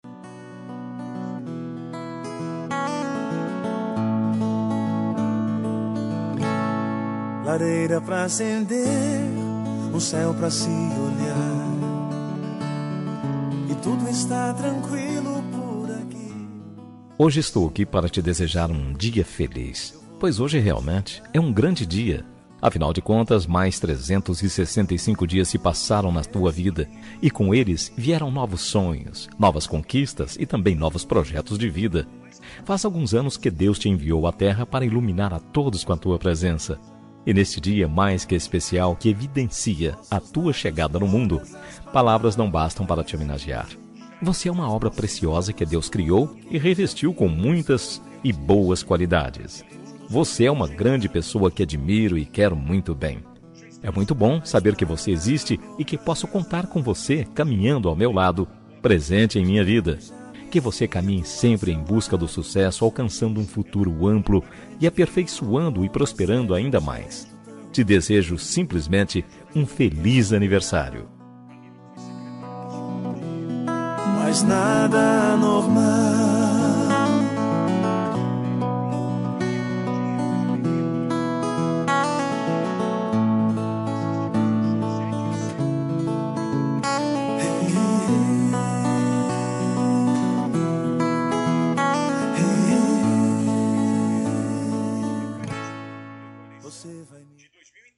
Aniversário de Pessoa Especial – Voz Masculina – Cód: 023546